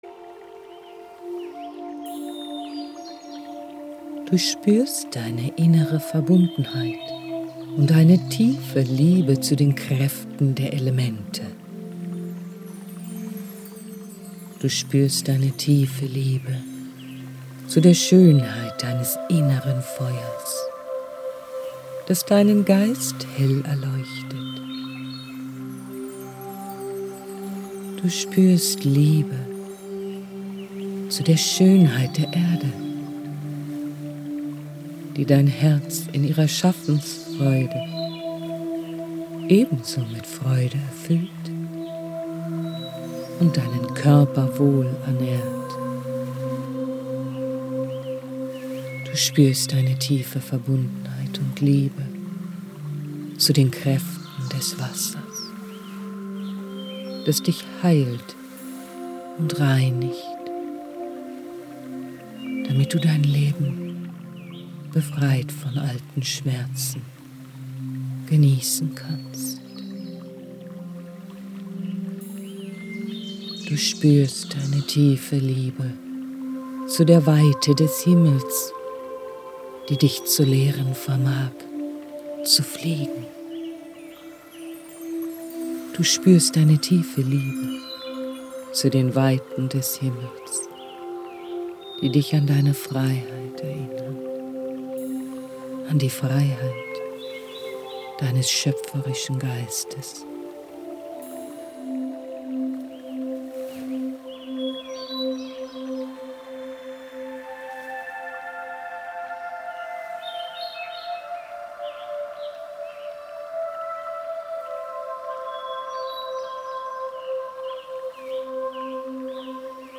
Durch die fein abgestimmte Kombination aus echten Naturklängen und heilsamen sphärischen Melodien erreichen Sie eine Veränderung der wissenschaftlich belegten Schwingungen in Ihrem Gehirn - von Beta-Wellen (38-15 Hz) zu Alpha-Wellen (14-8 Hz) hin zu Theta-Wellen (7-4 Hz).
Für einen optimalen Effekt empfehlen wir das Hören über Kopfhörer.
Schlagworte Die zwei Wölfe • Geführte Meditation • Indianer CD • Indianer Meditation • Indianer Spirit • Indianische Meditation • Indianische Weisheit • Indianische Weisheiten • Morgenmeditation • Morgen Meditation • Schamanismus